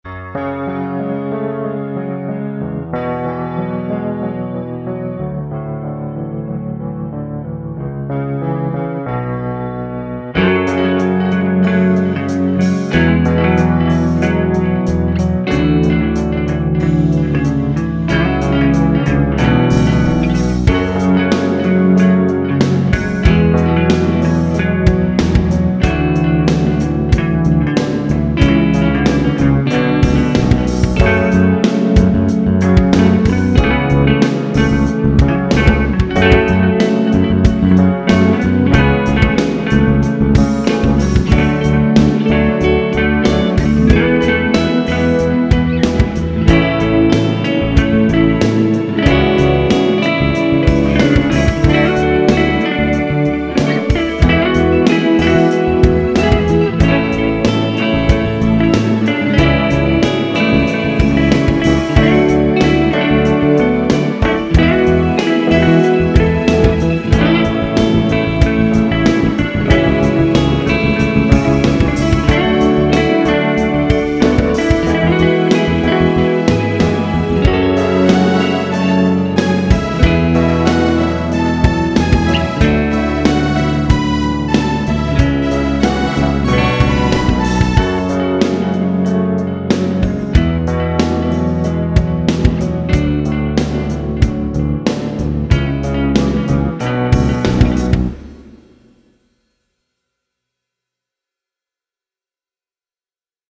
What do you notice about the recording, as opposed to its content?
Pitch is off in spots, the delay isn't synced appropriately, and the timing issues make everything worse.